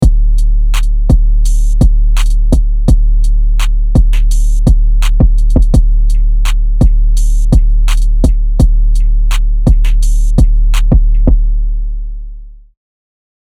Trap Drum Kit 02 + 808 Rumble